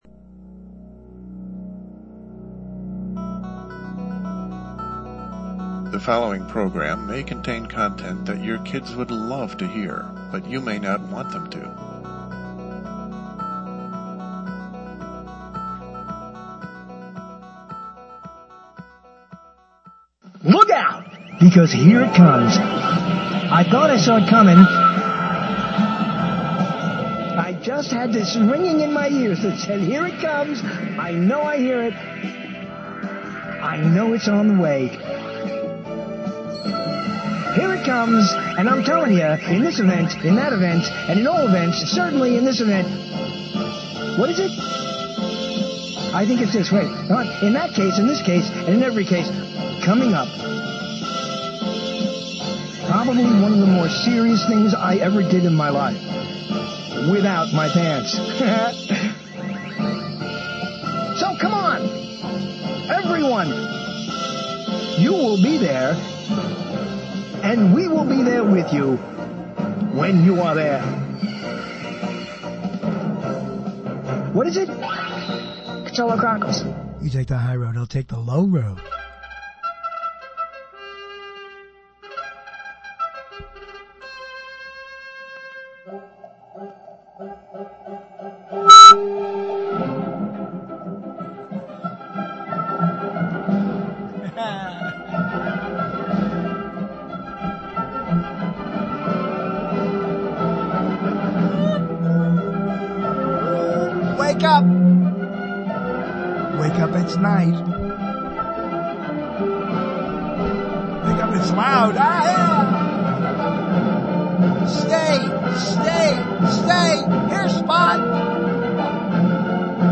On our Jan. 11 show, LIVE at 9:00 p.m. Eastern time, more talk about words. Recently, there was announced 2016’s Word of the Year, which is actually a phrase, which joins our language due to skips and jumps along the Hallelujah Trail.